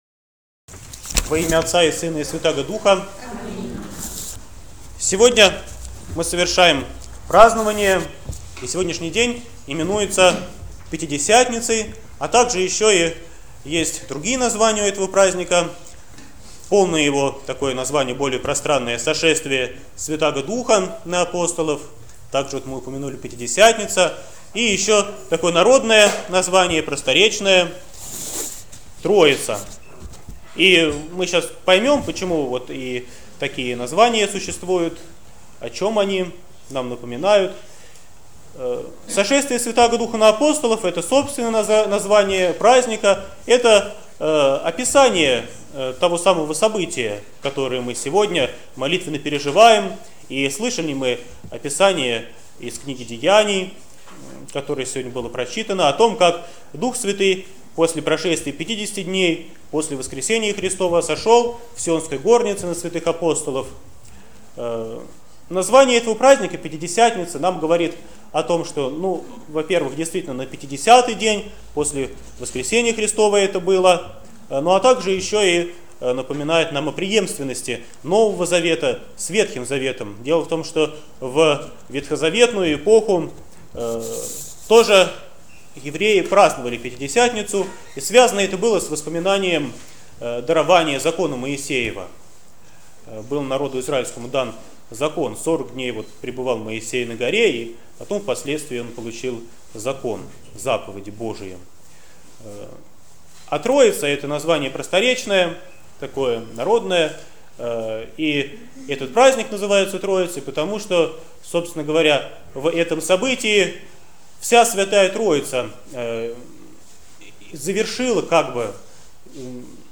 Проповедь на Троицу 2014